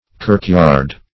kirkyard - definition of kirkyard - synonyms, pronunciation, spelling from Free Dictionary Search Result for " kirkyard" : The Collaborative International Dictionary of English v.0.48: Kirkyard \Kirk"yard`\, n. A churchyard.